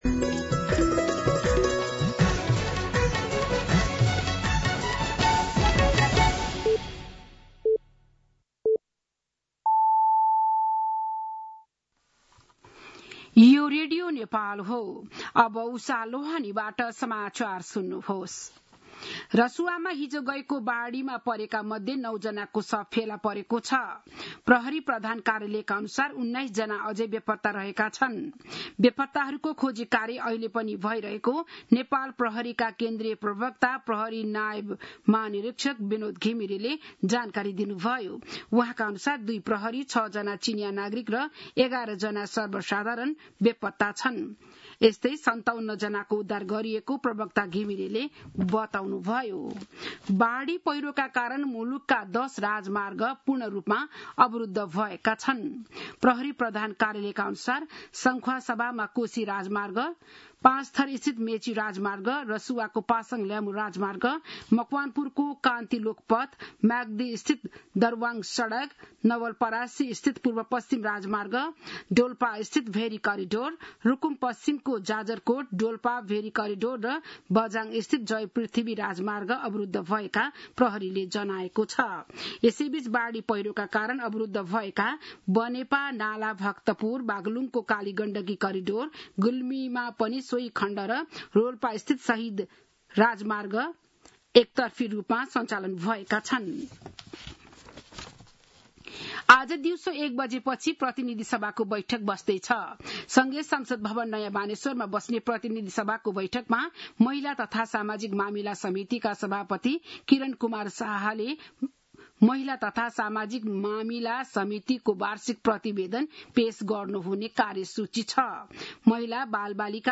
बिहान ११ बजेको नेपाली समाचार : २५ असार , २०८२